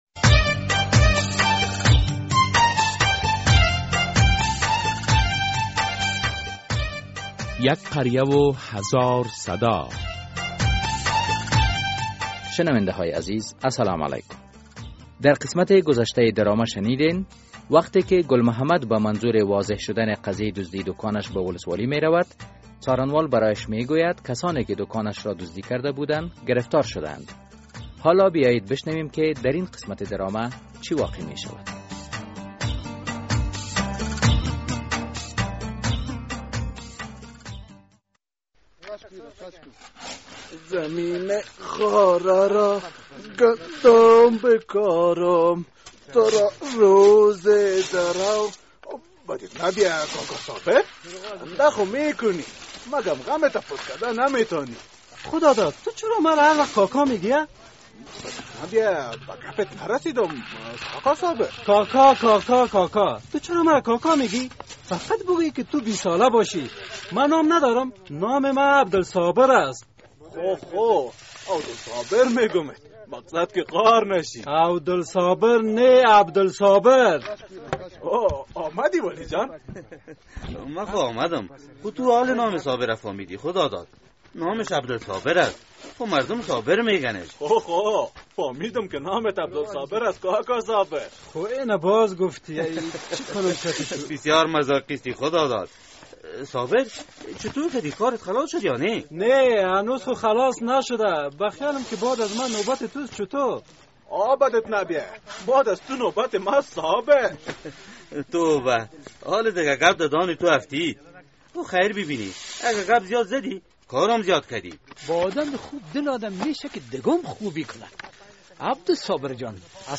در این درامه که موضوعات مختلف مدنی، دینی، اخلاقی، اجتماعی و حقوقی بیان می گردد هر هفته به روز های دوشنبه ساعت 3:30 عصر از رادیو آزادی نشر می گردد.